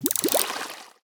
FishCaught.wav